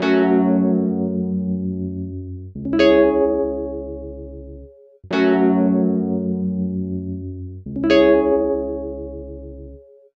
Ridin_ Dubs - Rhodes Key.wav